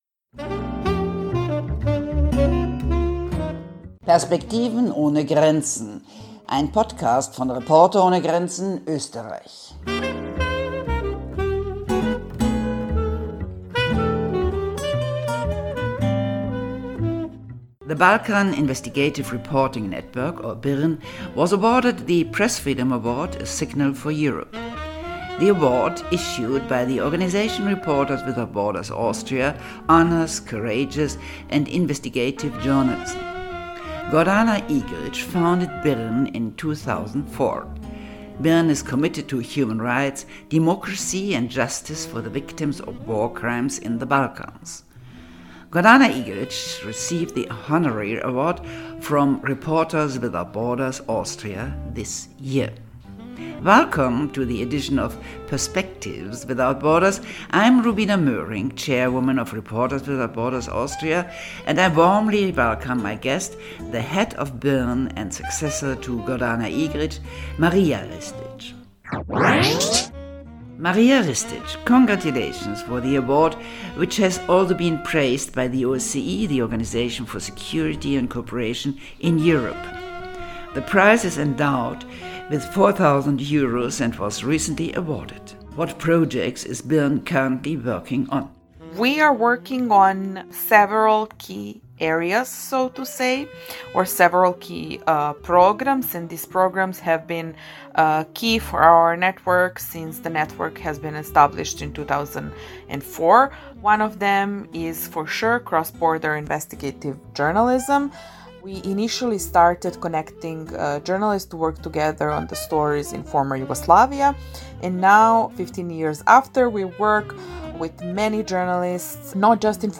Spannender Podcast-Talk